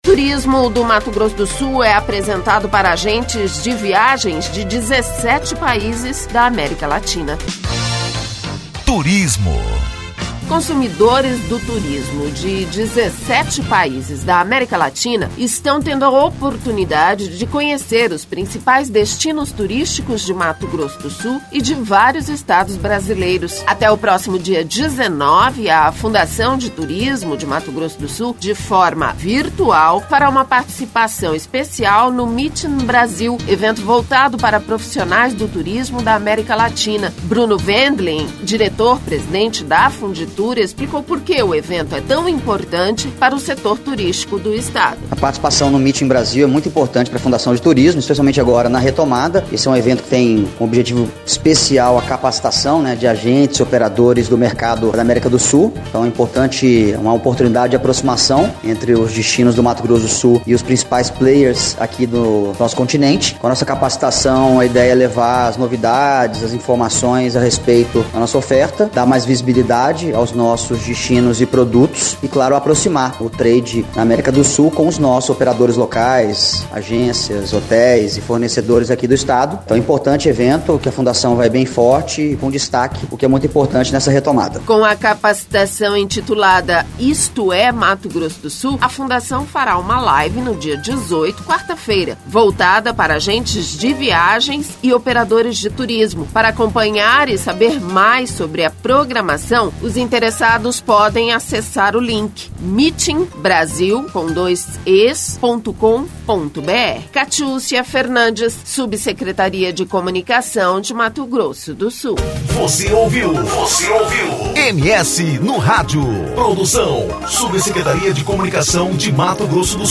Bruno Wendling, diretor presidente da Fundtur explicou porque o evento é tão importante para o setor turístico do Estado.